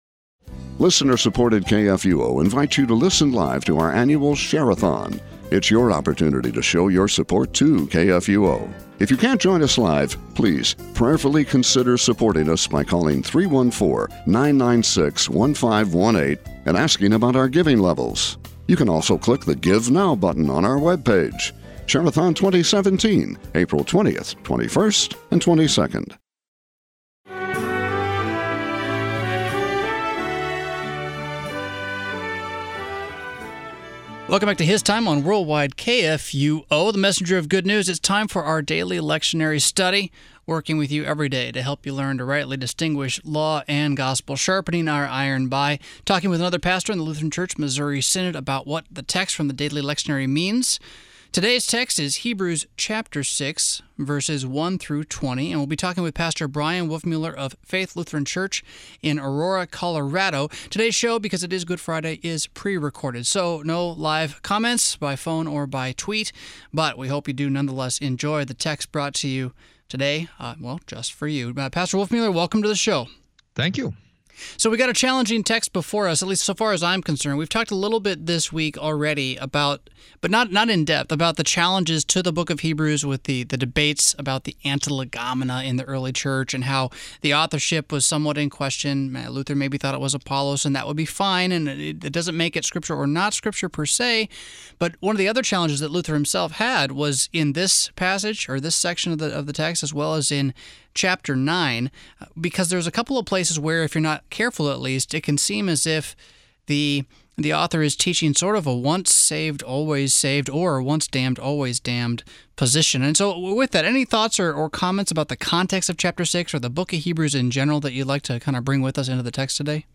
Morning Prayer Sermonette